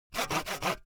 دانلود افکت صدای اره کوچک
افکت صدای اره کوچک یک گزینه عالی برای هر پروژه ای است که به صداهای صنعتی و جنبه های دیگر مانند اره، چوب و اره نیاز دارد.
Sample rate 16-Bit Stereo, 44.1 kHz
Looped No